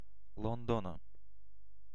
Ääntäminen
Ääntäminen : IPA : /ˈlʌn.dən/ UK : IPA : [ˈlʌn.dən] Haettu sana löytyi näillä lähdekielillä: englanti Käännös Ääninäyte Erisnimet 1.